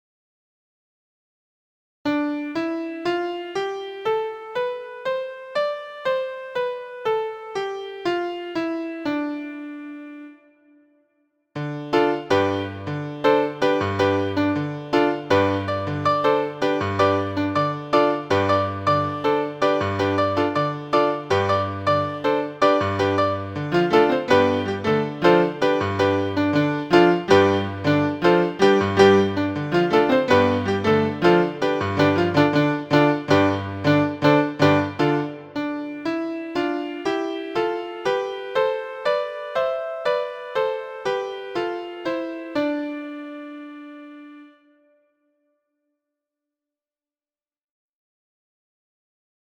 DORIAN
It has a minor triad built on the first scale degree and it is a commonly used scale in Rock, Jazz and some Folk styles.
Dorian.mp3